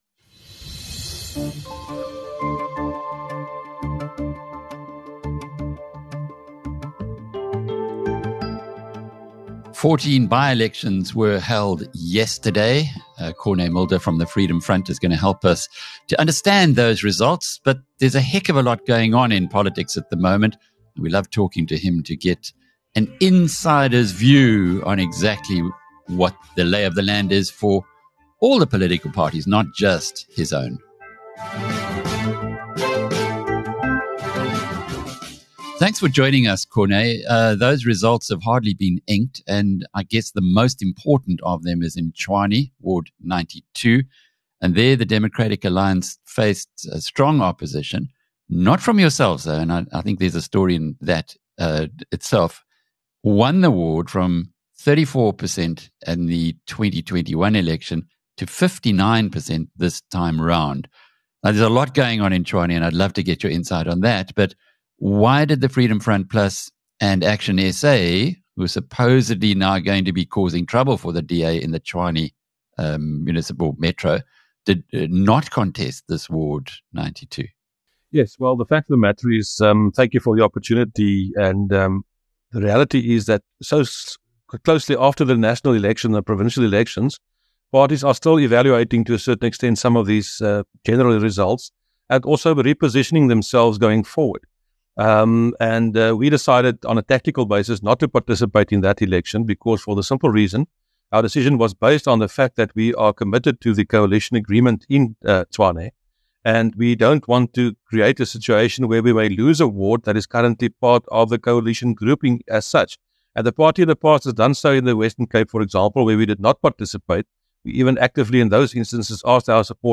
Freedom Front Plus Cape Leader Dr Corné Mulder provides the insider’s perspective on the results and other recent developments in SA’s fascinating political landscape.